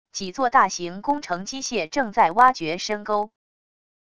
几座大型工程机械正在挖掘深沟wav音频